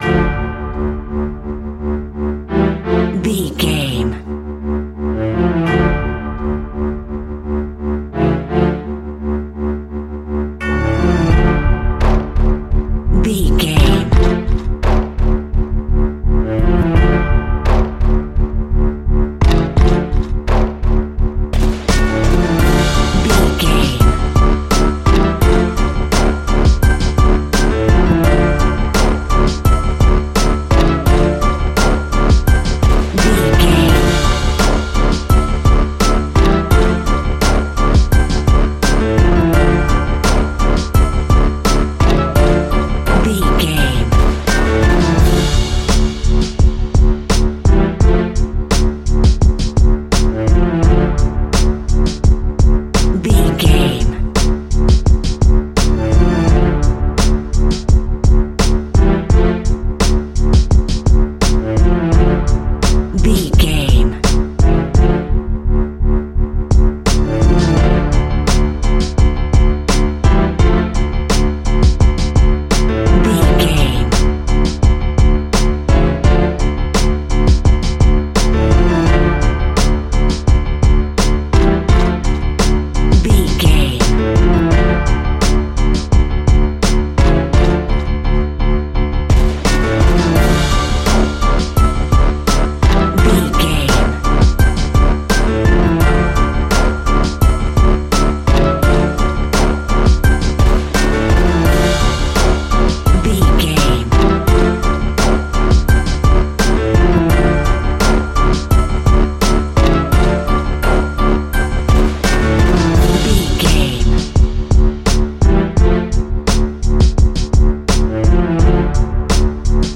Orchestral Fusion Hip Hop.
Epic / Action
Aeolian/Minor
WHAT’S THE TEMPO OF THE CLIP?
groove
hip hop drums
hip hop synths
piano
hip hop pads